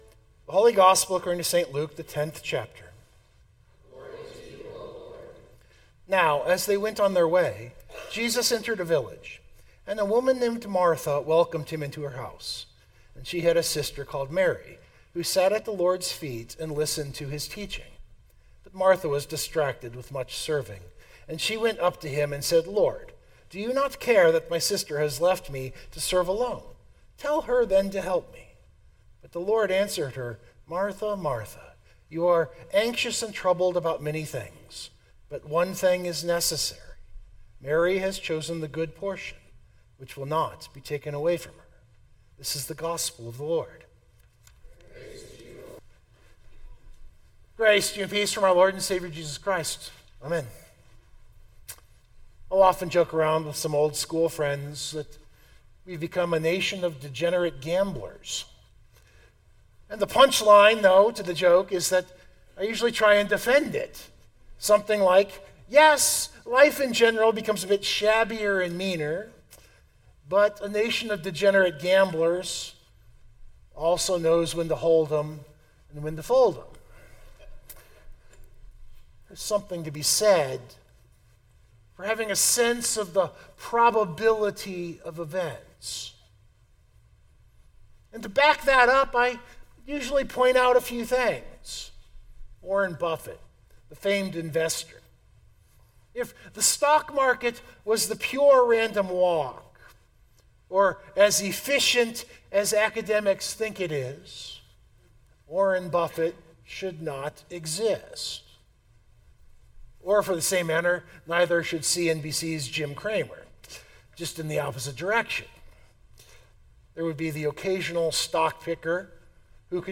072025 Sermon Download Biblical Text: Luke 10:38-42 Of all the biblical stories that cause complaint, Mary and Martha is right up there.